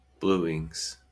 Ääntäminen
Ääntäminen US Haettu sana löytyi näillä lähdekielillä: englanti Käännöksiä ei löytynyt valitulle kohdekielelle. Bluings on sanan bluing monikko.